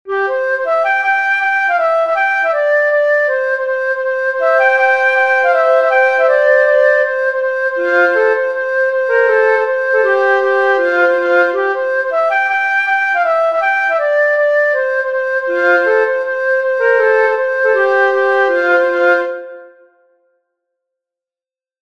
Musiche digitali in mp3 tratte dagli spartiti pubblicati su
Raccolta e trascrizioni di musiche popolari resiane